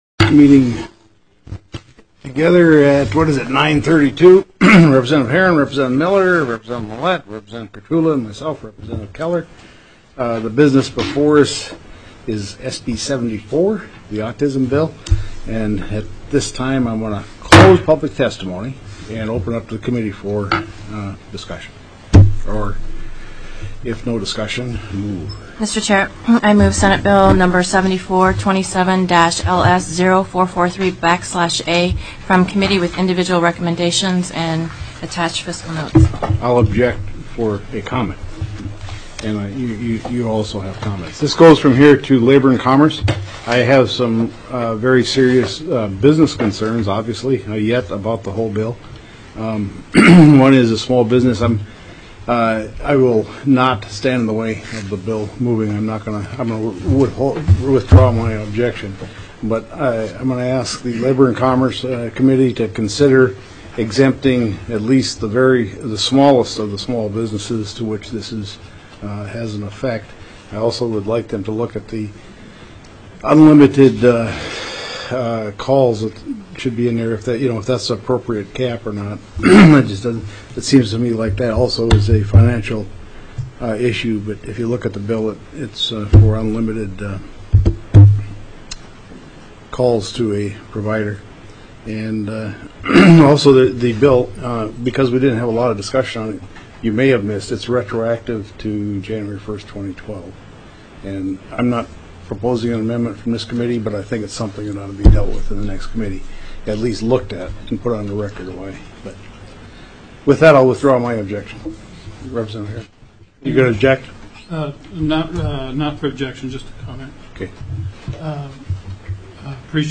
+ teleconferenced
CHAIR  WES KELLER  called the  House Health  and Social  Services
CHAIR KELLER closed public testimony.